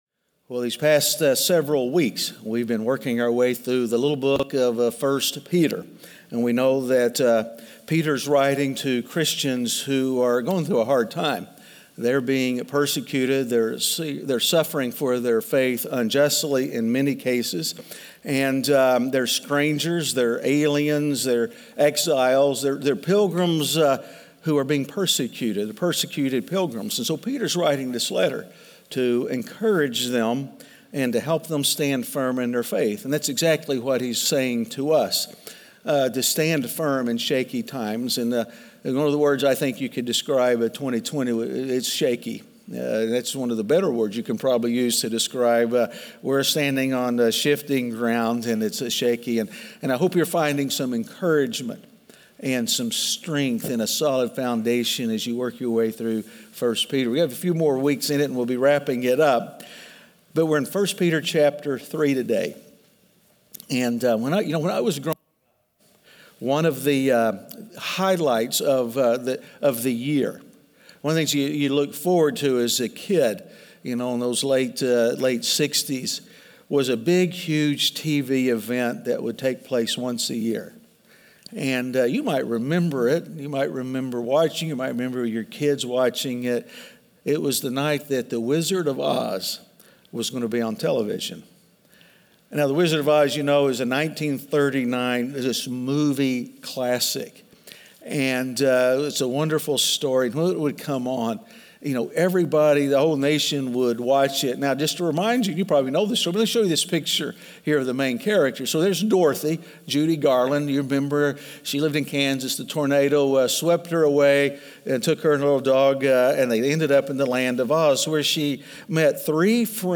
Standing Firm In Shaky Times (Week 11) - Sermon.mp3